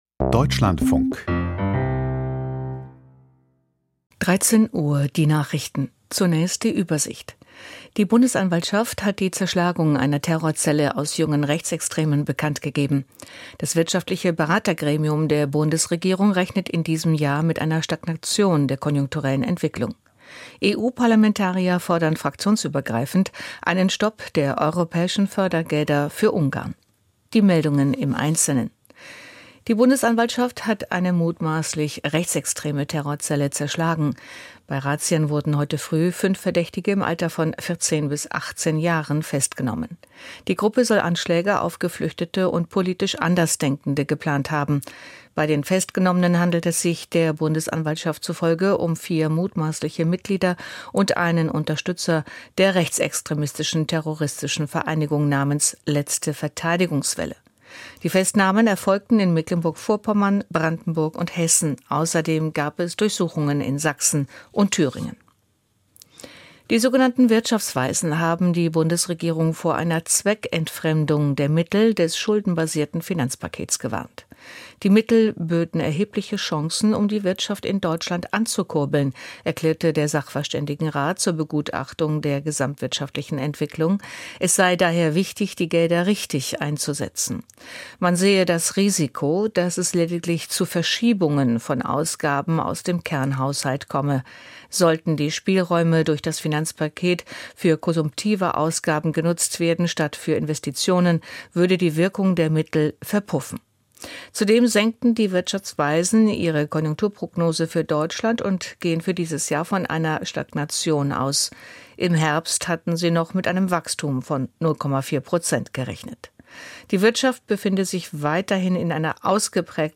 Die Nachrichten vom 21.05.2025, 13:00 Uhr